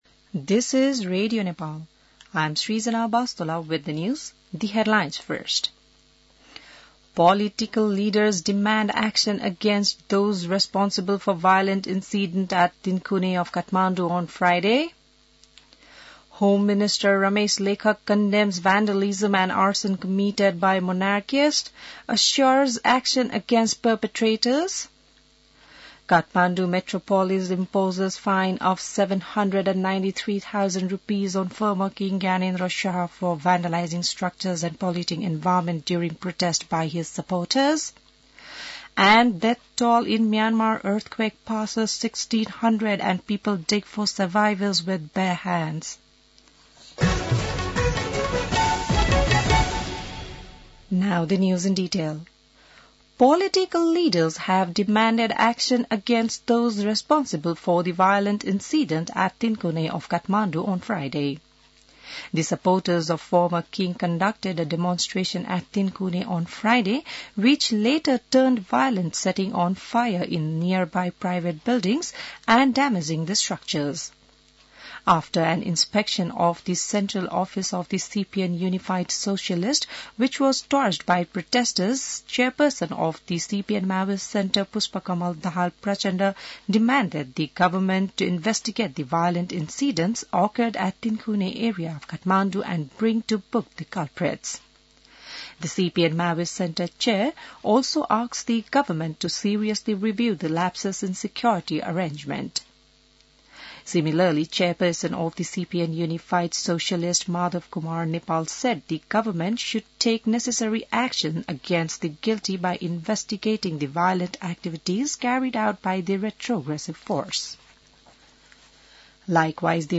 8 AM English News : 17 April, 2026